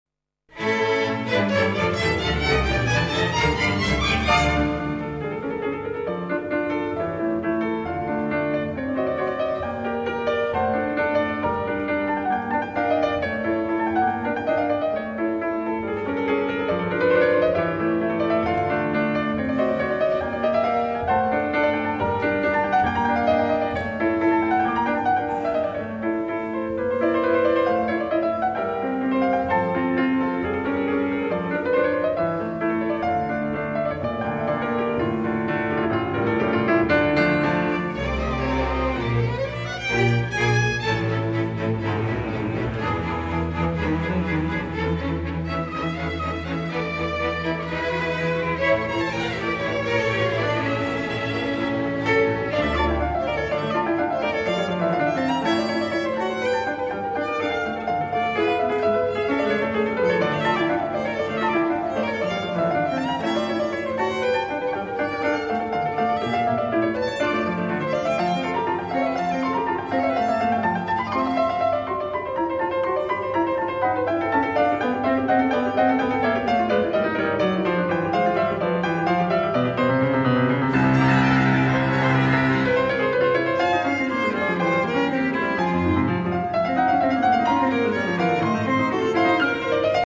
Ensemble orchestrale giovanile - Registrazione dal vivo di uno dei concerti del Val della Torre Festival (Torino).